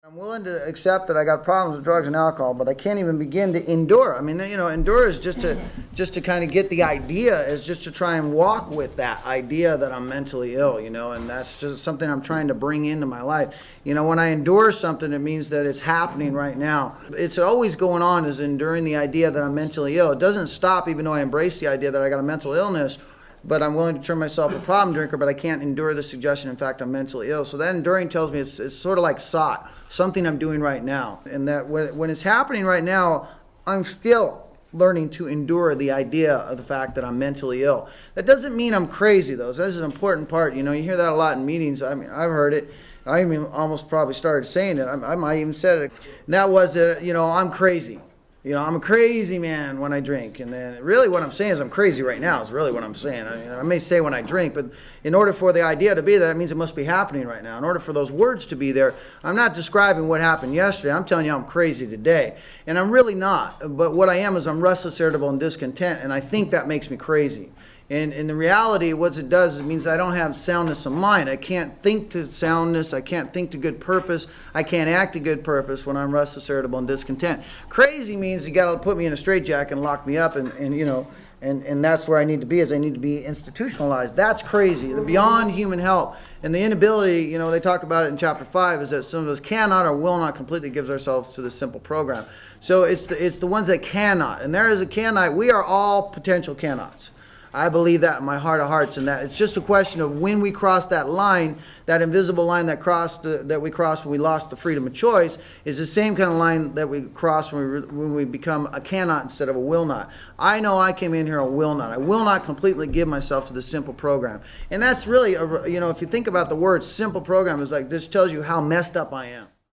The conversation breaks down the concept of emotionalism, where feelings whether elation, despair, or anger can become exaggerated and overwhelming, preventing individuals from staying grounded in the present.